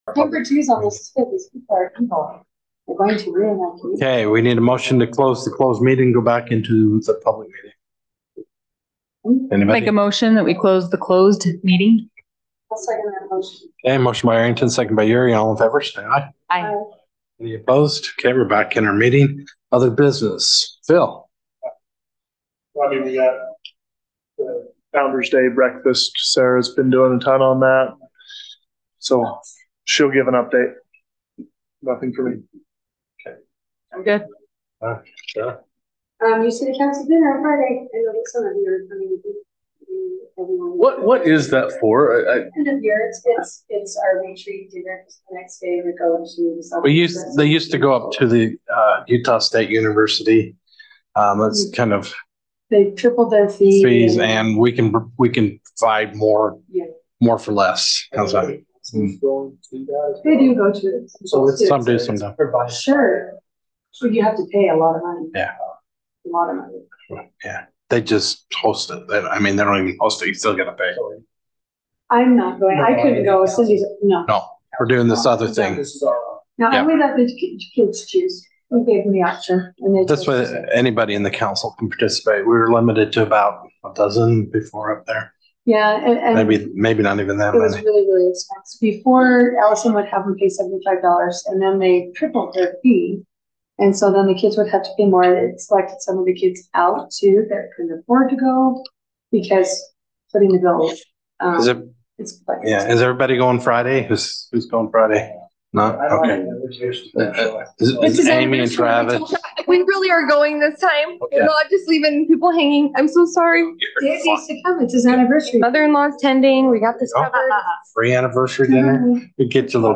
Notice, Meeting, Hearing
Adjournment Public Notice is hereby given that the City Council of Pleasant View, Utah will hold a Public Meeting in the city office at 520 West Elberta Dr. in Pleasant View, Utah on Tuesday, April 25, 2023, commencing at 6:00 PM.